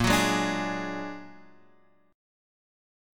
A#+7 chord